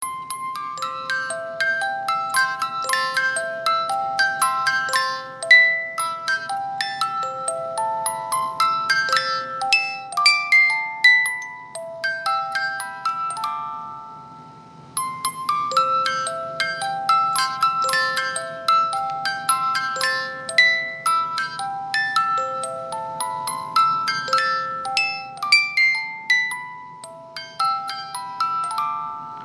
因為機械設計的限制，十八音梳機芯，只有十五秒左右的旋律，上發條後，這十五秒左右的旋律會重覆的撥放，直到發條鬆了為止！
機芯轉動時皆會有運轉聲、金屬磨擦聲(電動機芯也會有馬達運轉聲)，請知悉。